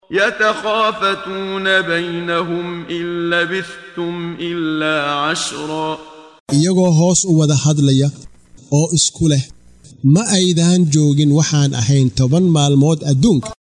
Waa Akhrin Codeed Af Soomaali ah ee Macaanida Suuradda Ta Ha oo u kala Qaybsan Aayado ahaan ayna la Socoto Akhrinta Qaariga Sheekh Muxammad Siddiiq Al-Manshaawi.